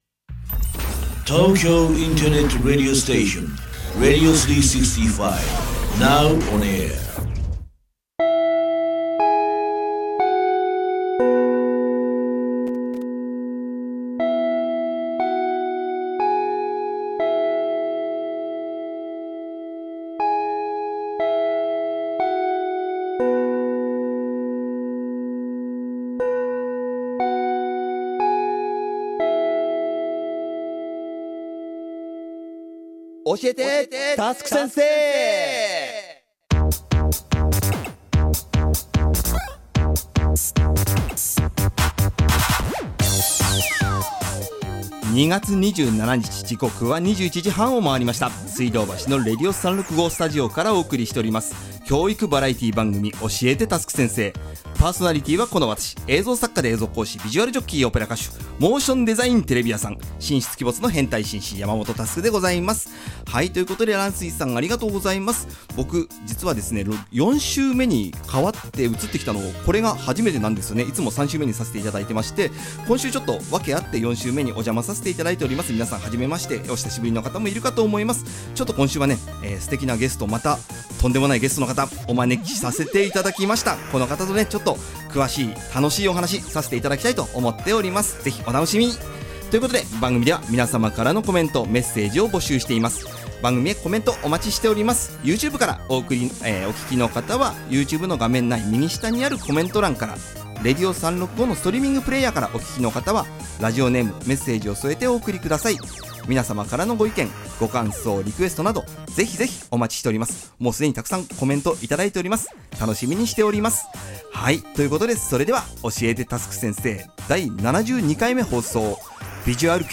コーナー1：クリエイターズトーク